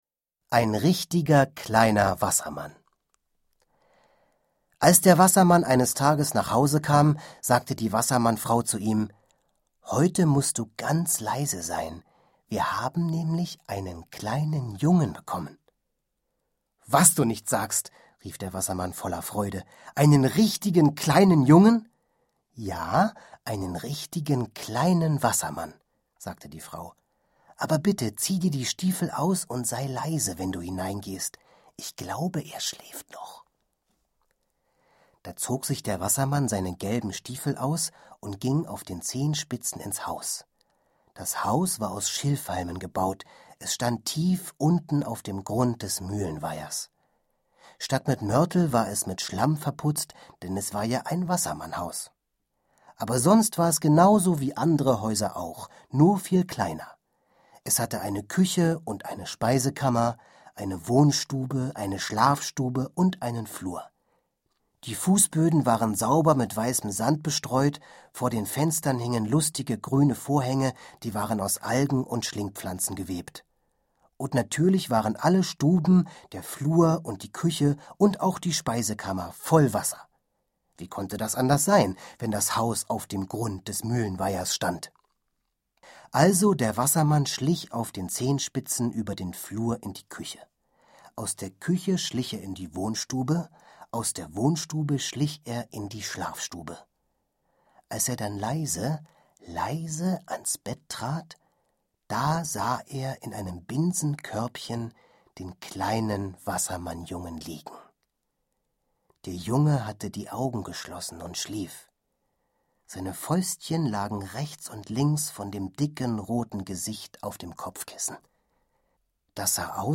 Florian Lukas (Sprecher)